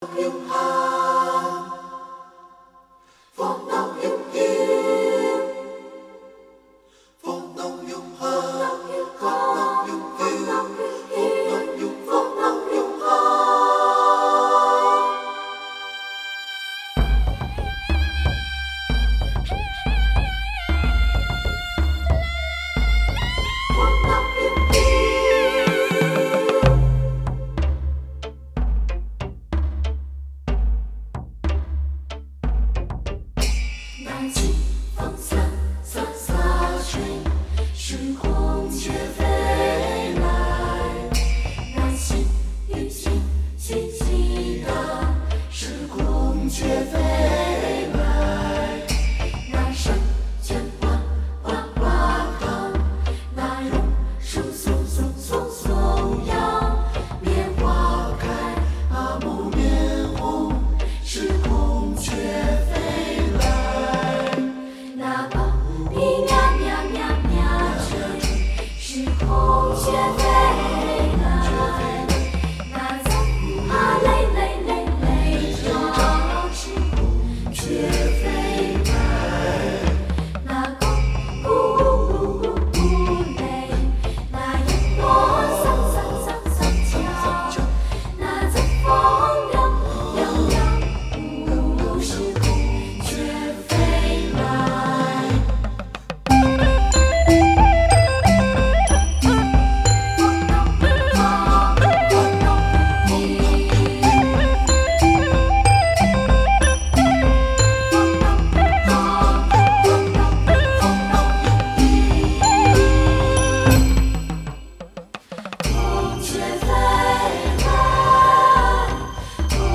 中國音樂、發燒天碟